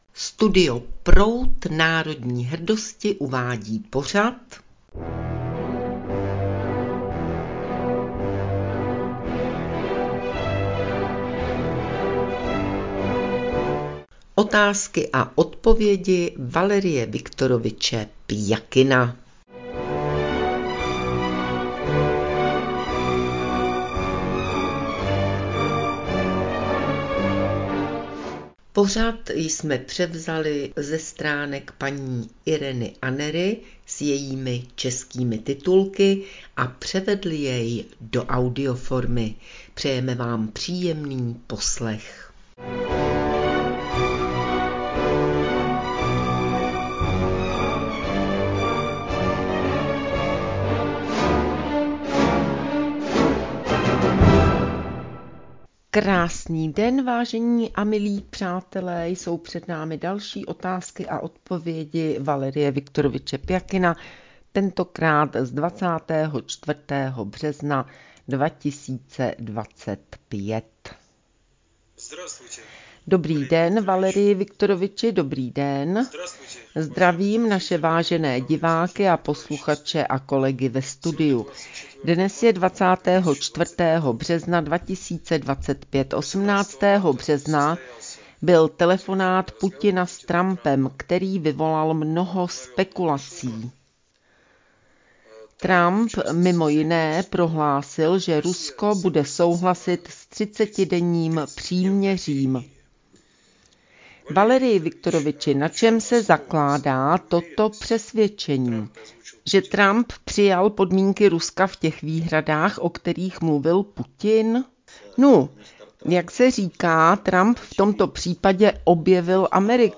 s českým dabingom